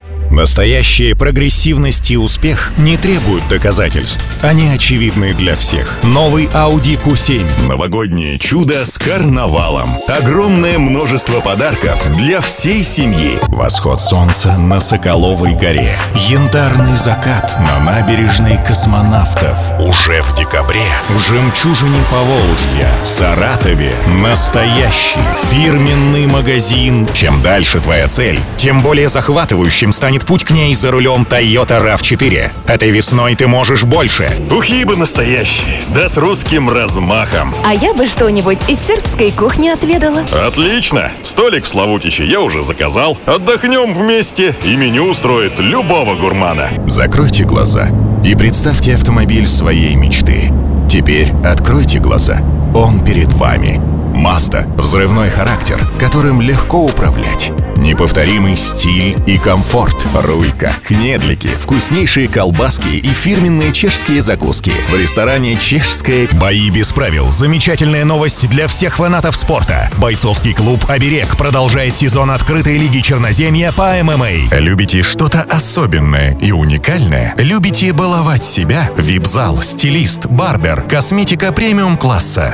Тракт: Микрофон Neumann TLM103, Ламповый предусилитель/компрессор - Presonus ADL700.
Акустически обработанная панелями voice-booth ("микрофонка").
Демо-запись №1 Скачать